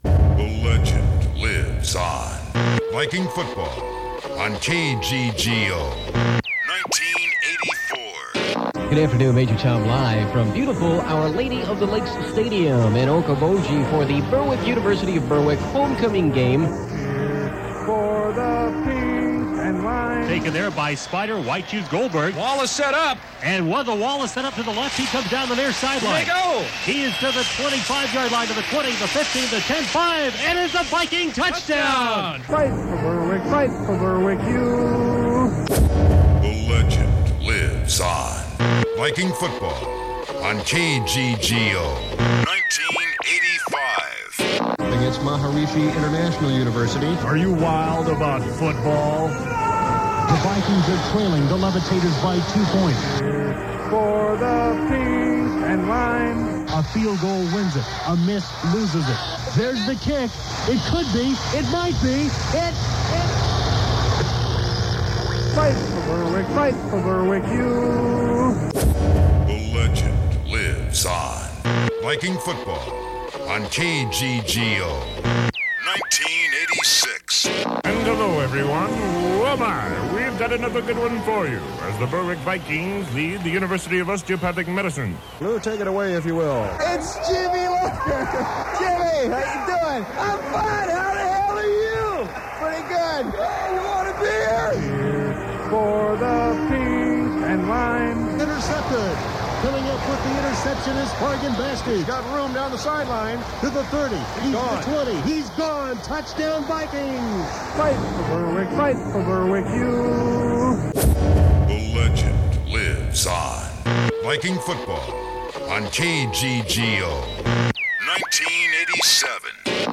This week marks the 41st anniversary of the first broadcast of the iconic Berwick Homecoming game.